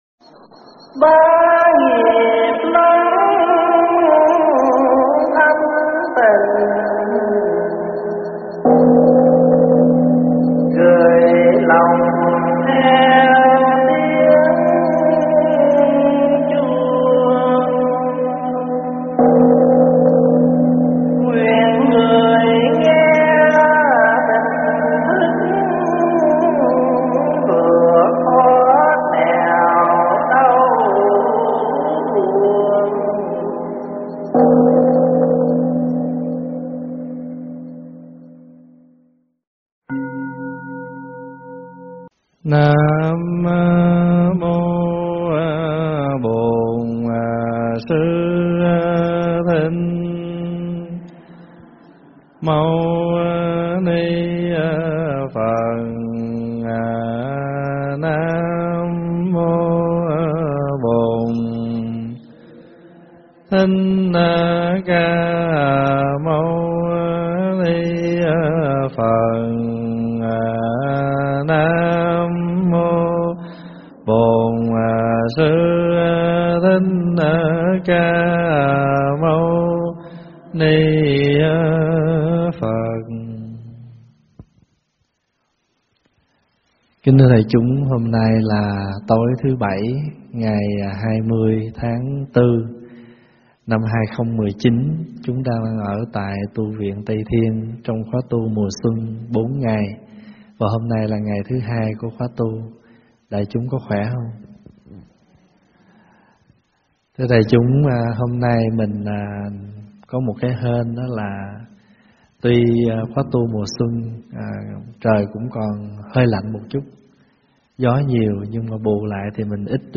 Mp3 vấn đáp Biết Lo
tại TV Tây Thiên